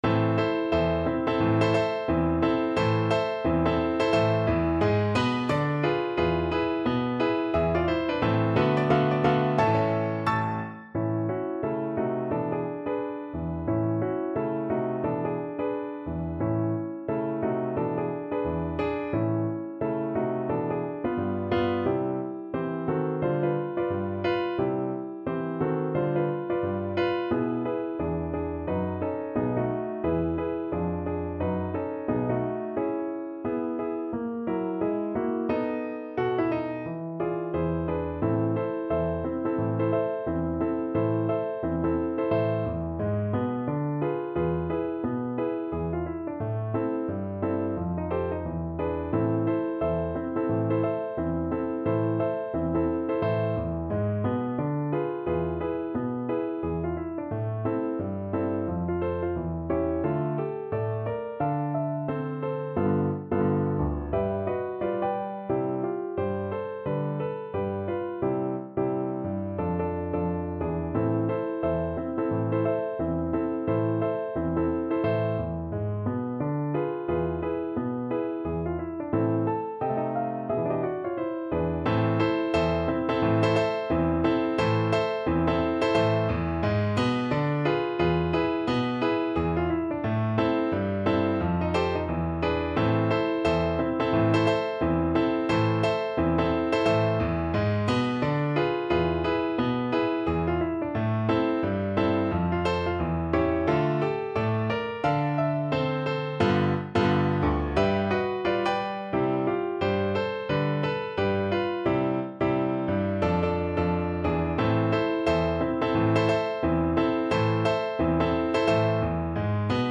~ = 176 Moderato
Jazz (View more Jazz Viola Music)